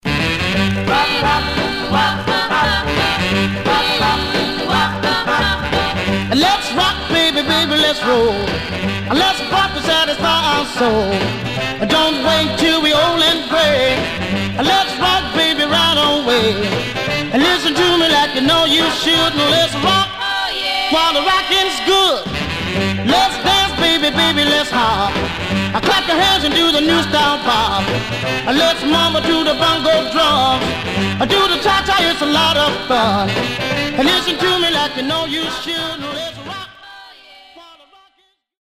Stereo/mono Mono
Rythm and Blues Condition